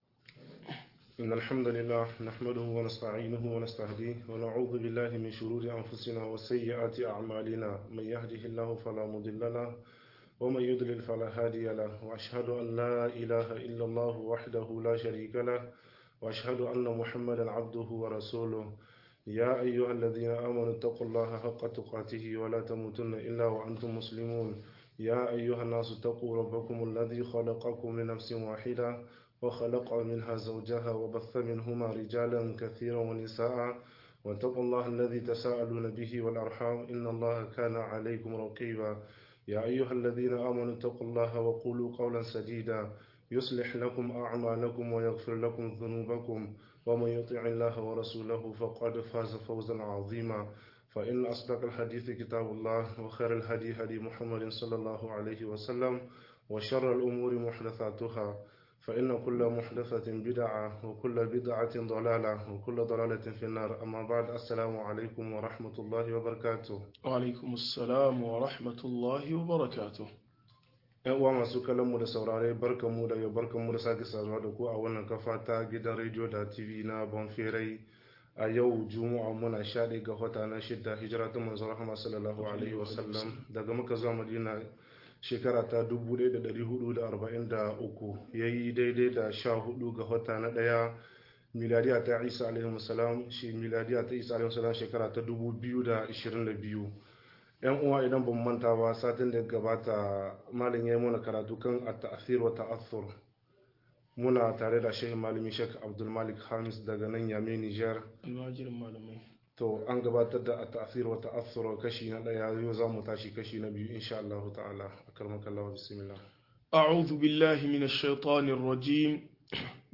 Yin tasiri da tasirantuwa-02 - MUHADARA